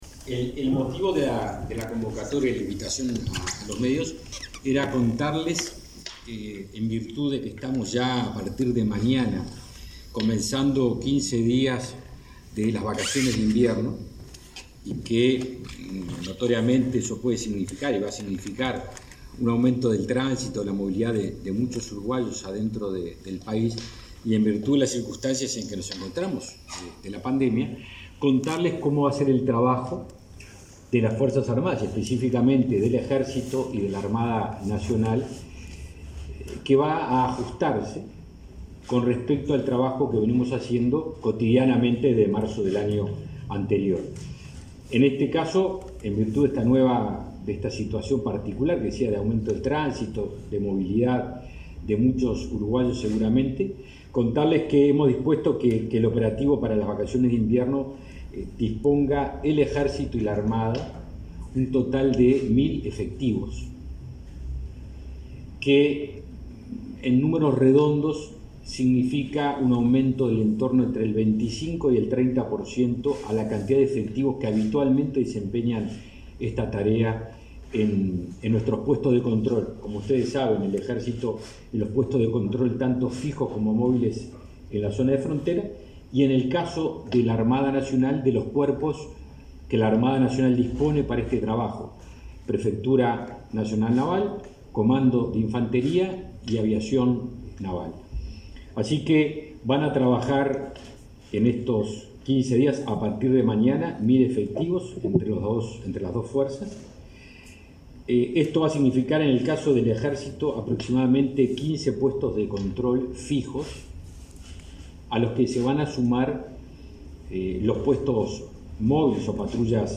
Conferencia de prensa del ministro de Defensa, Javier García